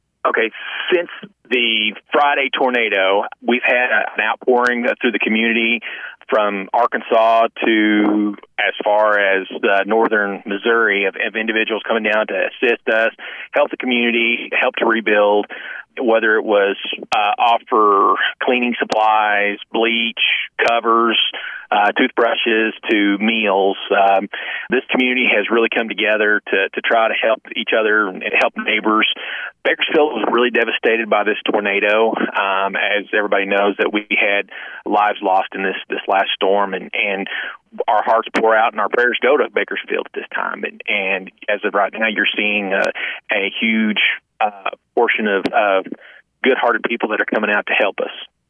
Ozark County Sheriff Cass Martin recorded with us and addressed just that, recalling the outpouring of people who have offered resources and labor to those in need.